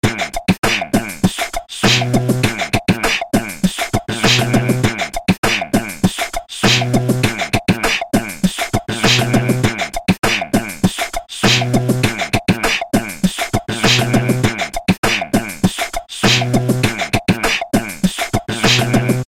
Ну если даже скрин с паттерном сделал, то наверно сам)...и да да, звуки тоже мои)
Хех, да какой тут урок, записать свои звуки и расположить их в почти случайном порядке даже блондинка сможет)
FLbeatbox.mp3